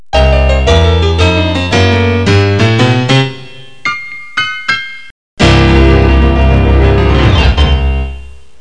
klavier.mp3